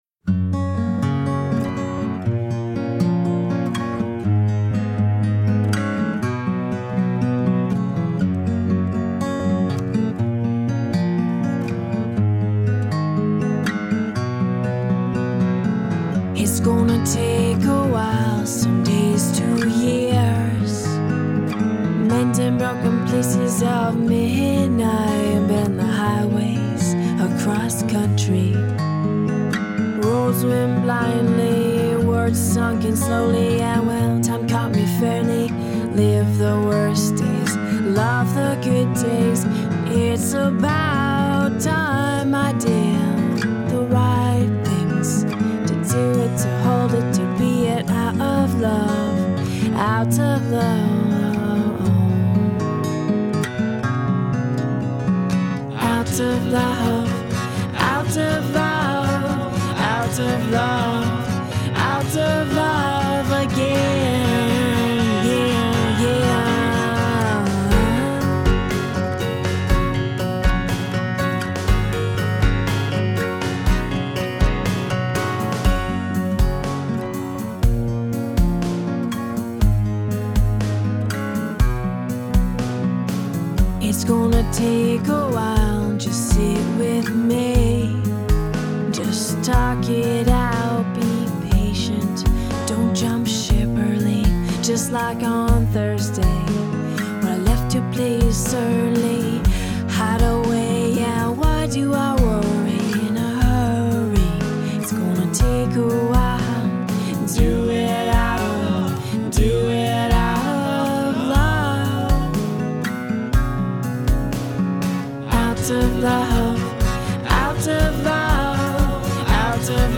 folk singer